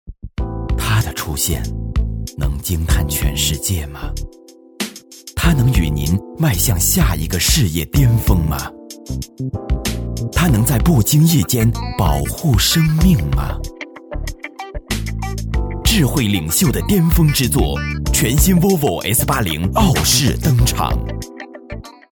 男声 Male Voice-公司名
男S386 广告-沃尔沃S80-汽车广告-磁性 大气